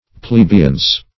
Search Result for " plebeiance" : The Collaborative International Dictionary of English v.0.48: Plebeiance \Ple*be"iance\, n. 1.